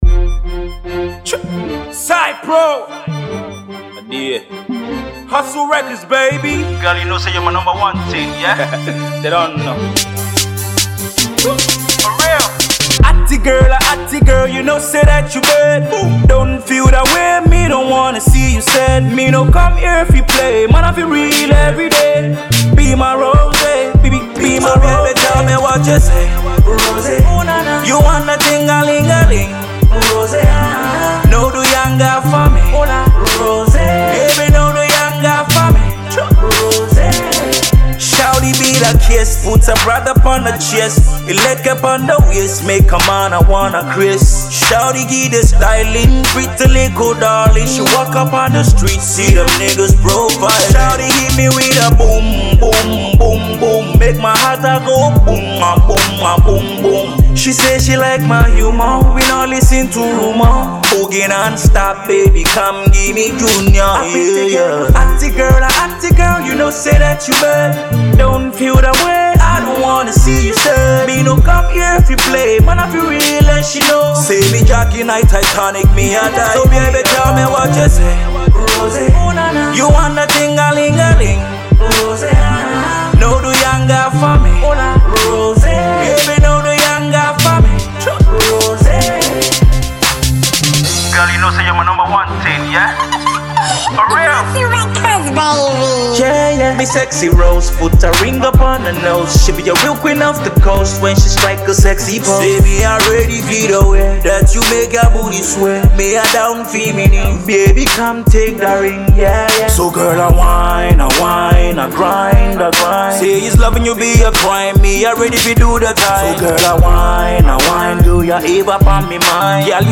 With a captivating melody